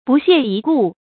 bù xiè yī gù
不屑一顾发音
成语正音 屑，不能读作“xuè”。